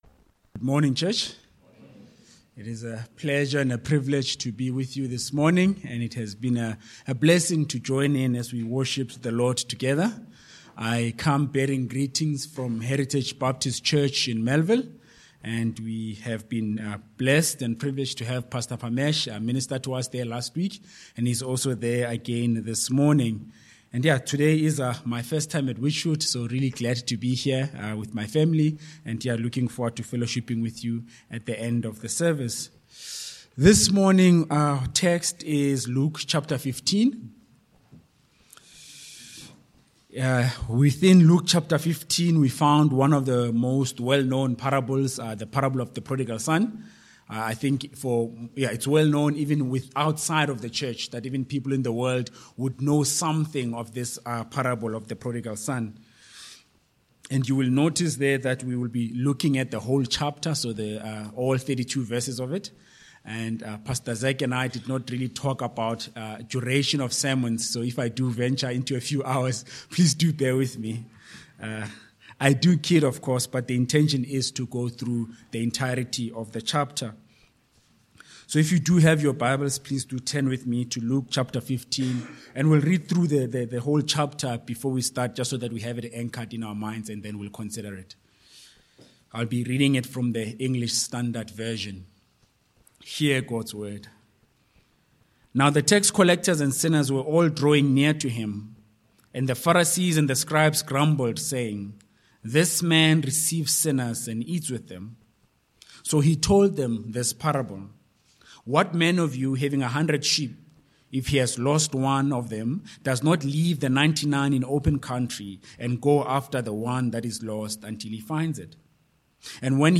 Sermon Points: 1. The Context/Question v1-2